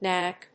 フナック